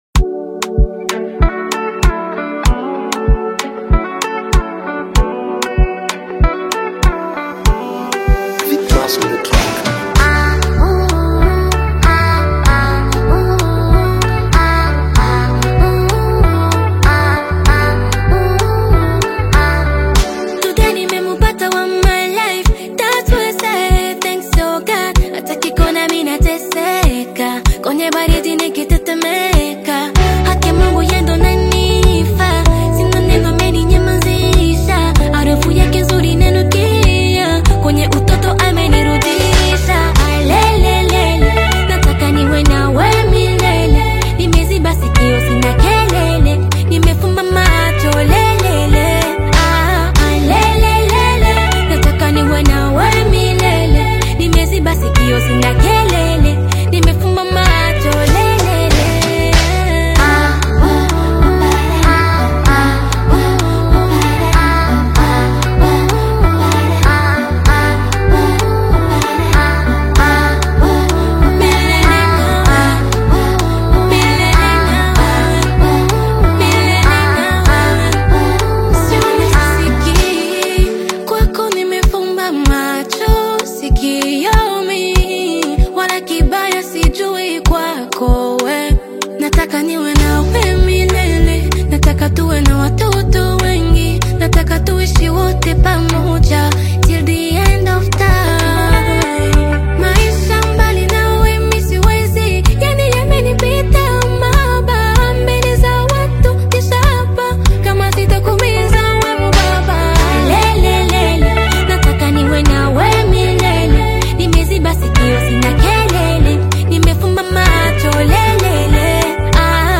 uplifting Tanzanian gospel single
powerful Swahili praise lyrics
soulful vocal delivery